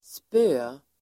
Uttal: [spö:]